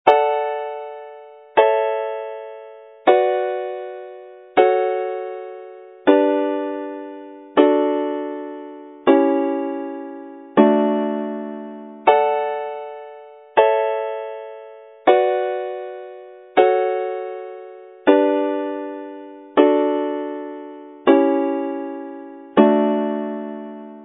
yn D